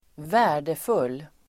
Uttal: [²v'ä:r_deful:]